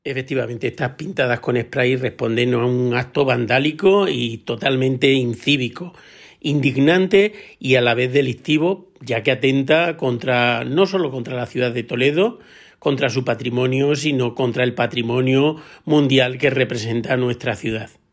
AUDIO. Teo García, concejal de Cultura y Patrimonio Histórico